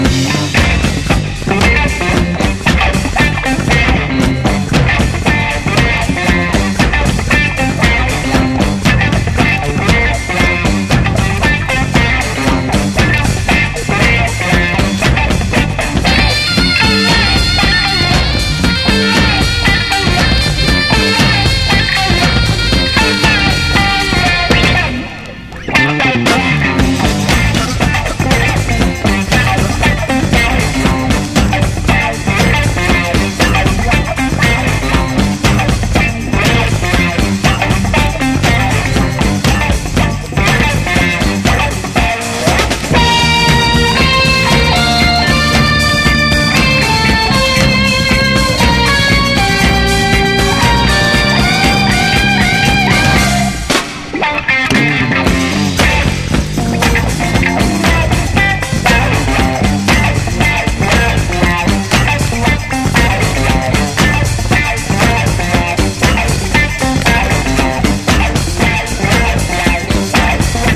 JAZZ / DANCEFLOOR / JAZZ FUNK / FUSION / RARE GROOVE / PIANO